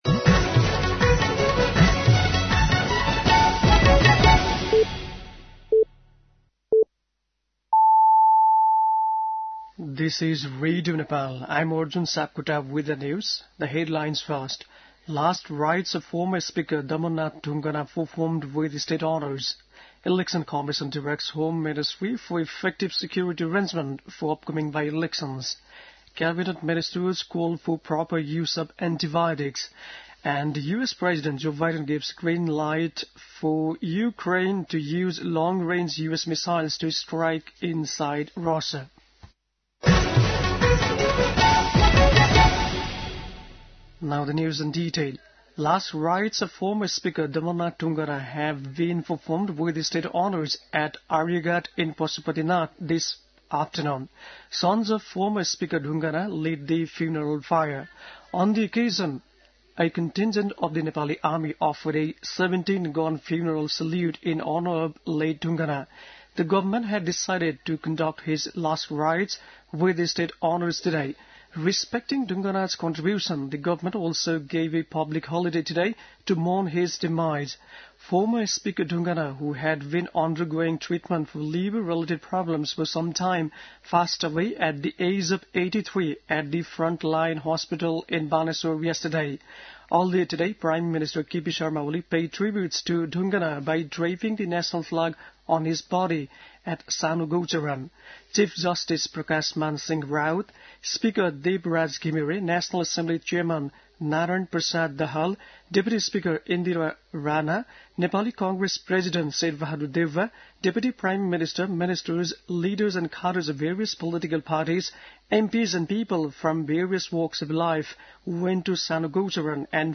बेलुकी ८ बजेको अङ्ग्रेजी समाचार : ४ मंसिर , २०८१
8-pm-english-news-8-03.mp3